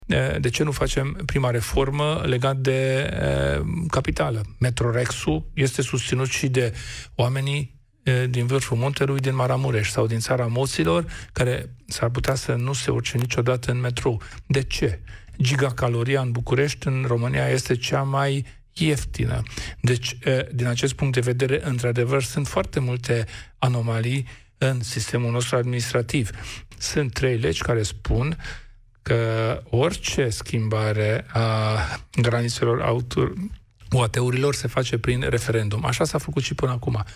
Reforma administrativă nu trebuie făcută din pix și nici în grabă – spune la emisiunea „Piața Victoriei”, la Europa FM, președintele UDMR, Kelemen Hunor.